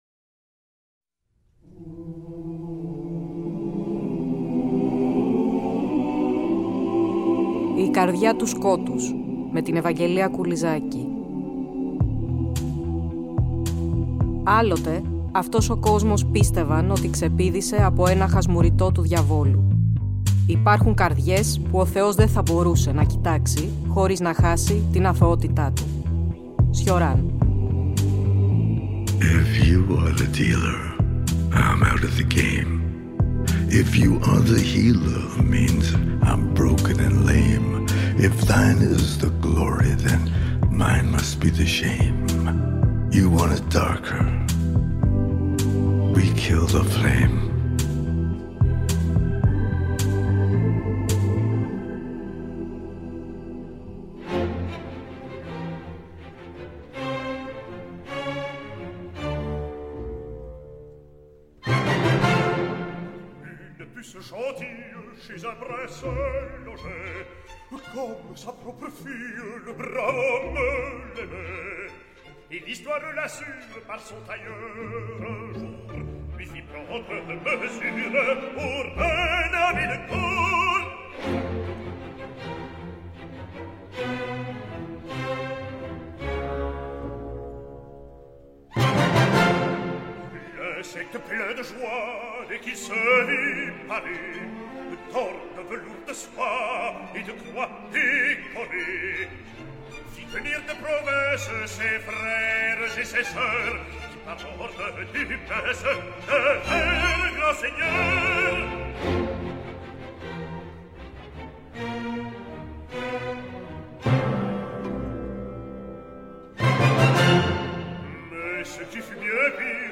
Πλαισιώνουμε μουσικά (και) με αποσπάσματα από το «υβριδικό» έργο του Hector Berlioz (“ légende dramatique en quatre parties “, το χαρακτήριζε ο ίδιος) “La Damnation de Faust” .